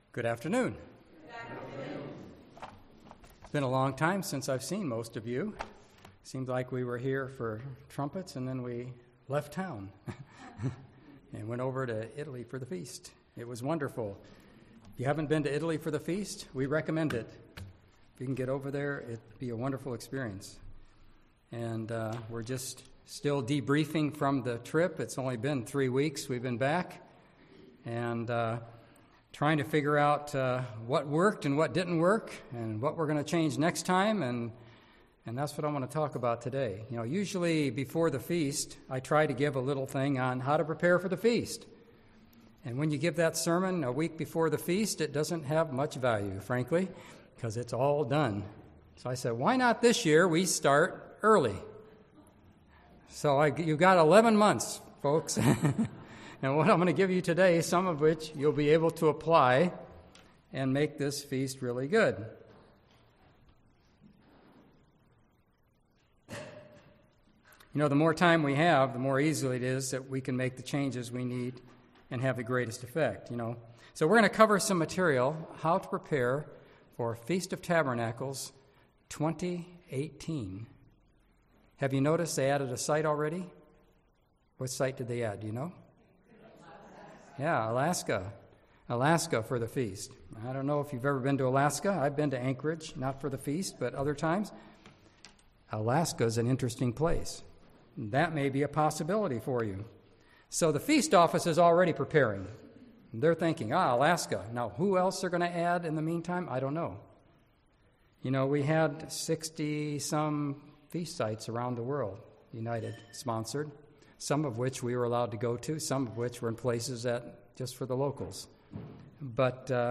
Listen to this sermon to find out how, why and when you should prepare to keep the Feast of Tabernacles.